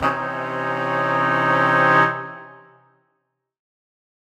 Index of /musicradar/undercover-samples/Horn Swells/C
UC_HornSwell_Cmajminb6.wav